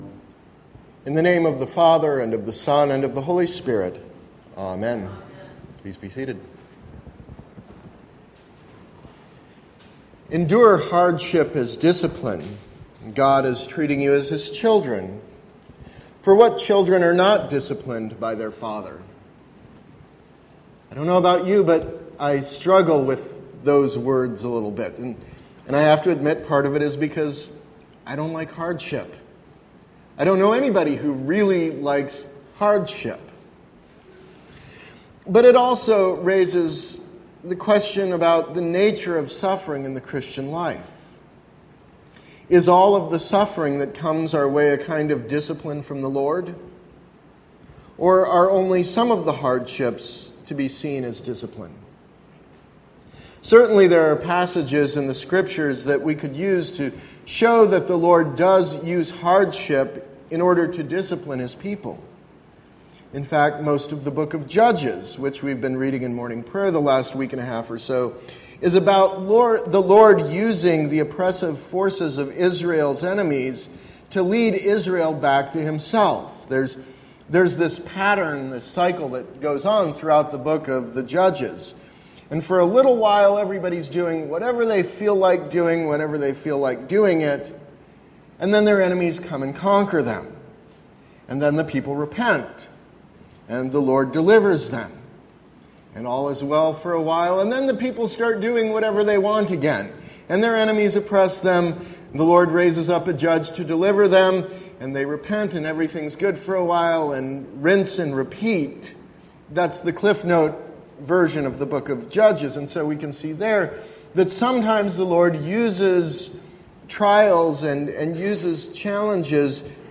Sermon – August 14, 2016